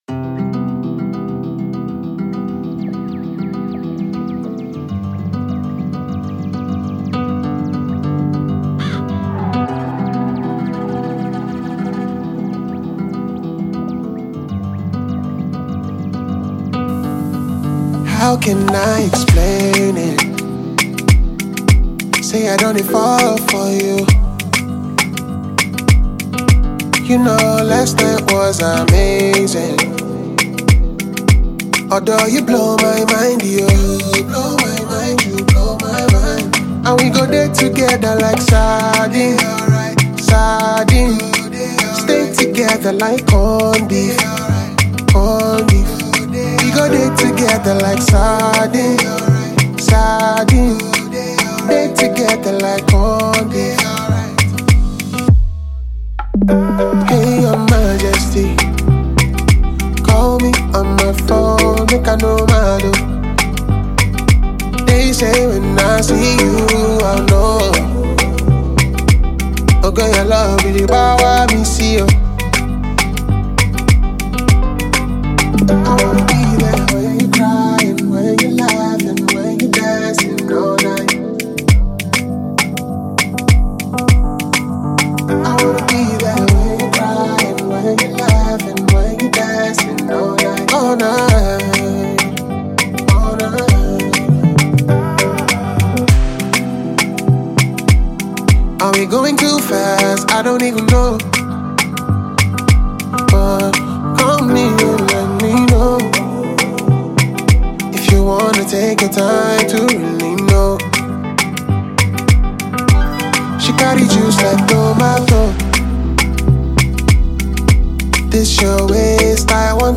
R&B, Afrobeat
G Maj